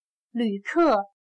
旅客/Lǚkè/Huésped de un hotel, viajante, pasajero, turista.